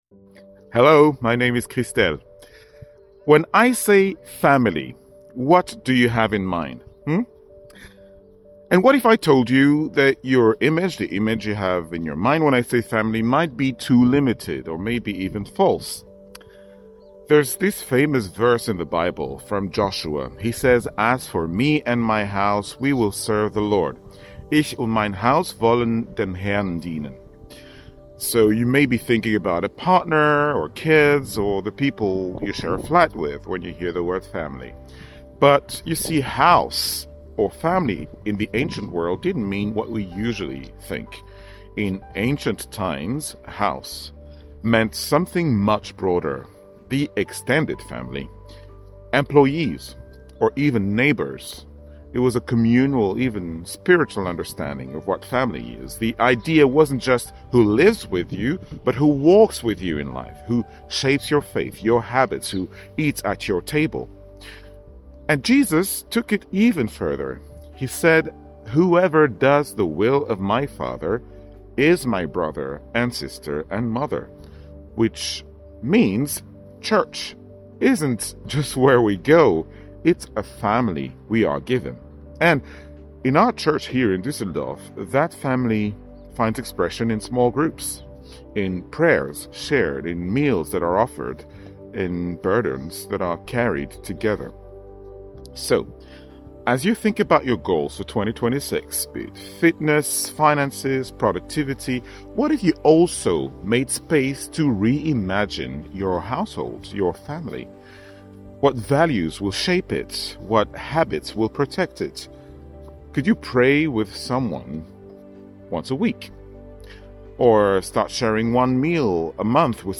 Tag 8 der Andacht zu unseren 21 Tagen Fasten & Gebet